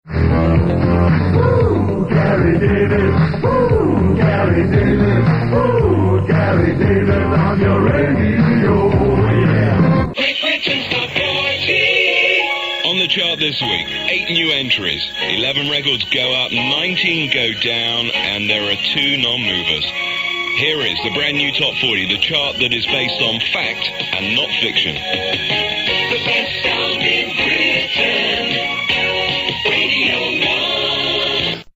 Another voice from the end of the bright red and blue Radio 1 era. Gary Davies was a 1979 recruit at his hometown’s Piccadilly Radio, before joining Radio 1 in 1982.